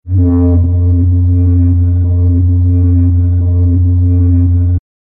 Sci-fi-laser-weapon-hum-sound-effect.mp3